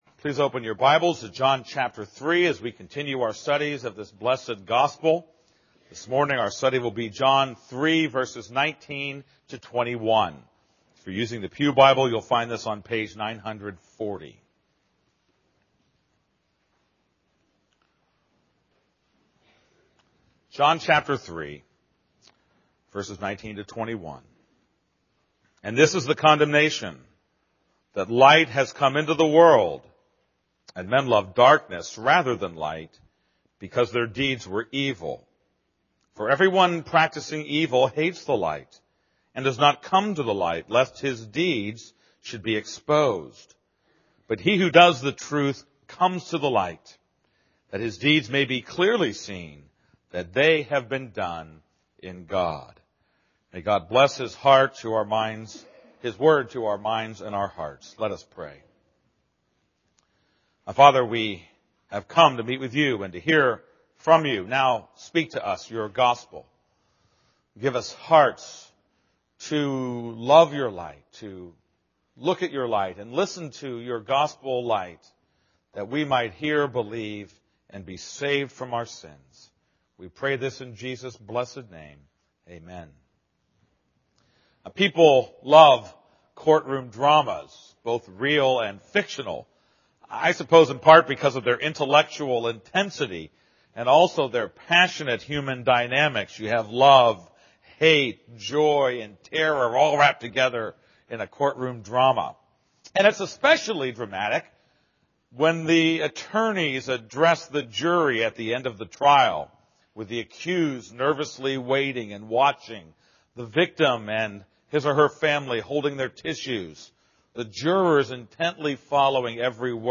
This is a sermon on John 3:19-21.